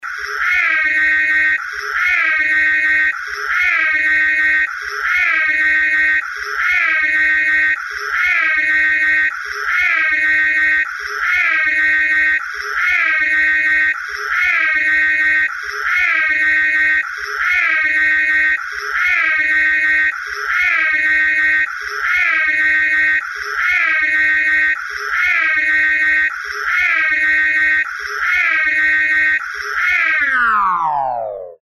Сирена включается и затем отключается